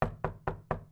KnockonDoor